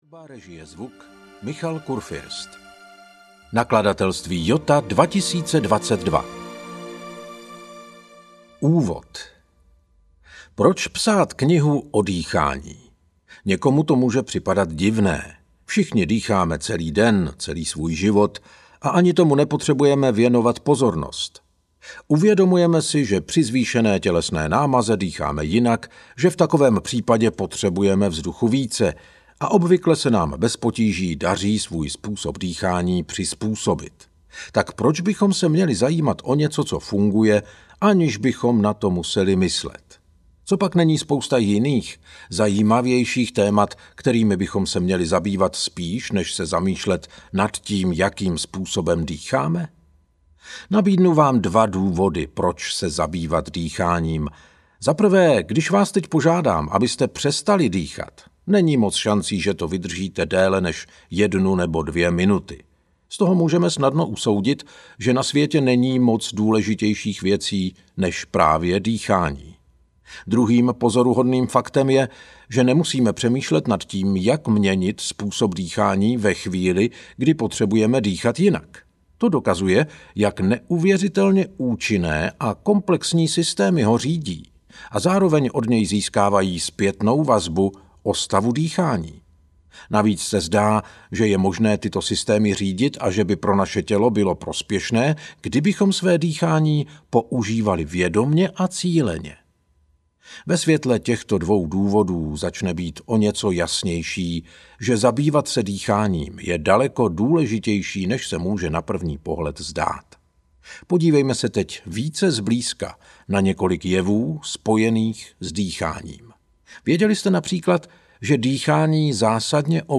Naučte se správně dýchat audiokniha
Ukázka z knihy